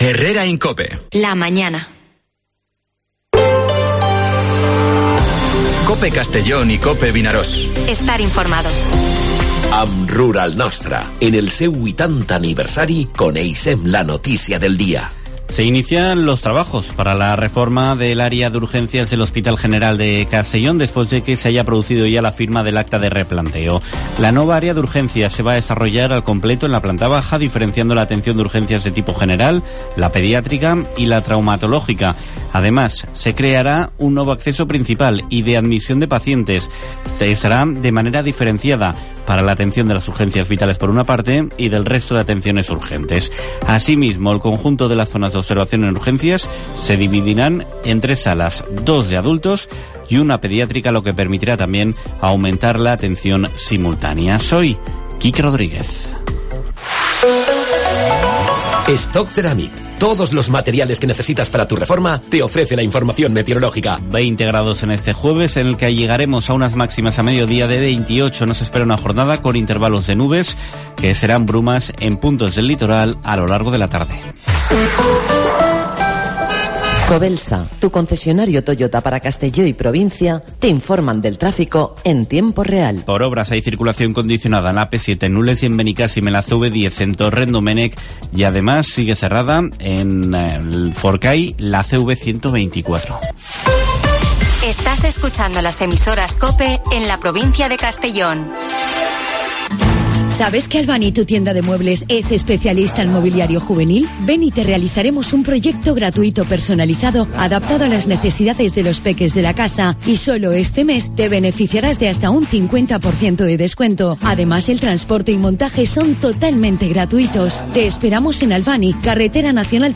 Informativo Herrera en COPE en la provincia de Castellón (28/09/2023)